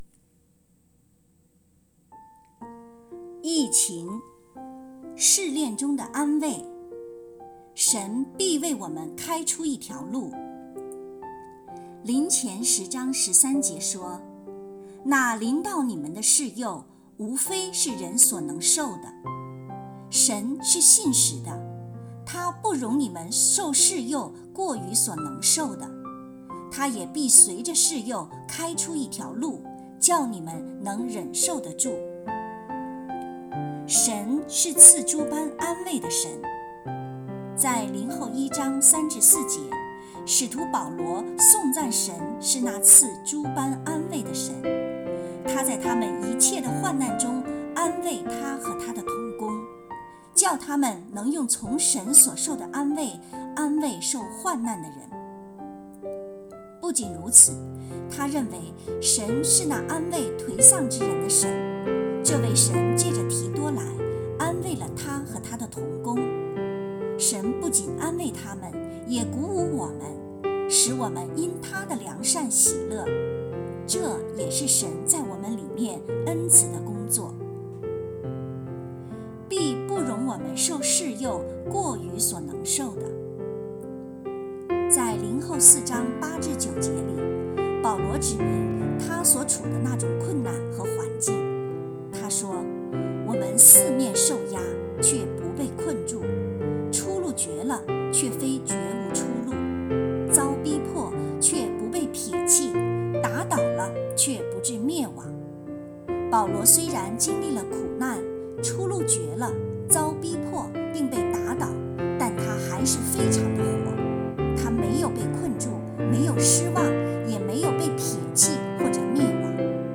有声版